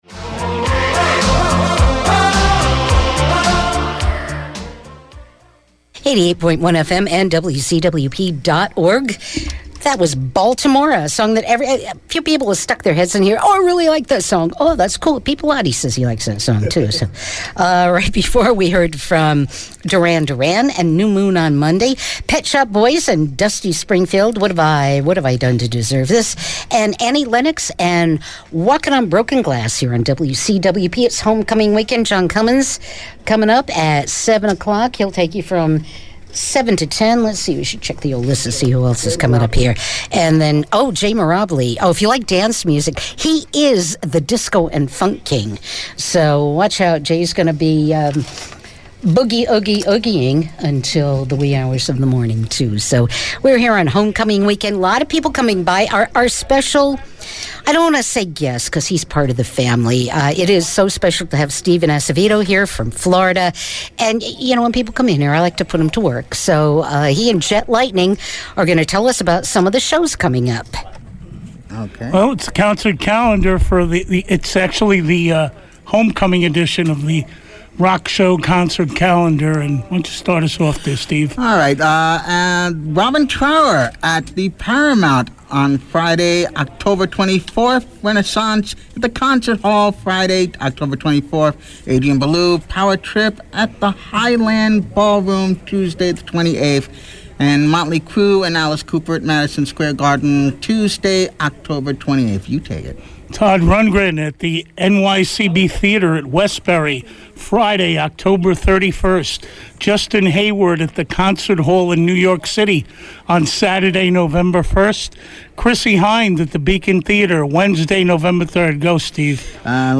took turns reading the WCWP Rock Show concert calendar